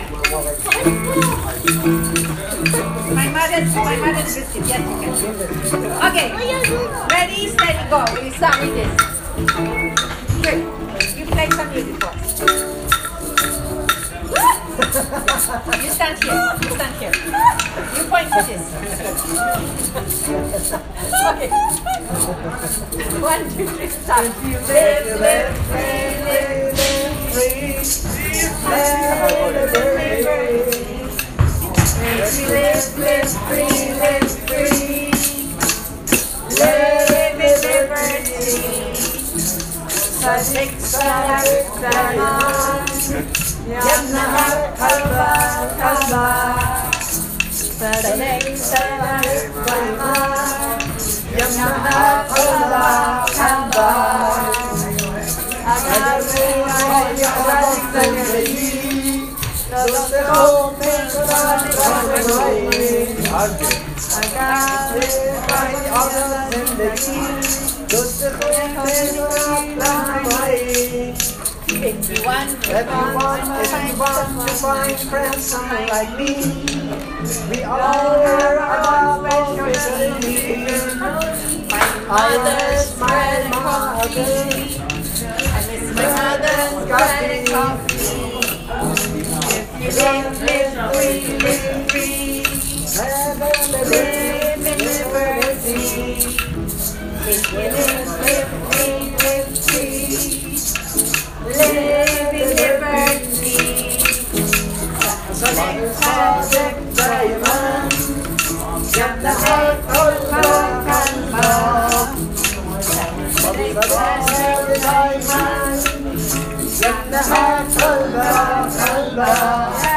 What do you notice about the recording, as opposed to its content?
These are recordings from the center that show the process of writing the song: liberty-singthrough.m4a